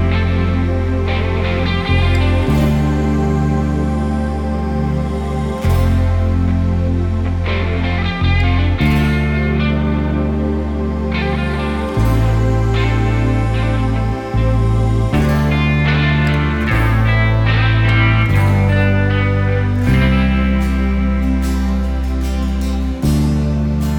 no whistle Soft Rock 5:10 Buy £1.50